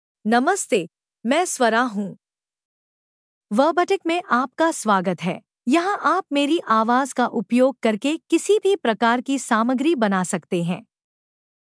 Swara — Female Hindi (India) AI Voice | TTS, Voice Cloning & Video | Verbatik AI
SwaraFemale Hindi AI voice
Voice sample
Listen to Swara's female Hindi voice.
Swara delivers clear pronunciation with authentic India Hindi intonation, making your content sound professionally produced.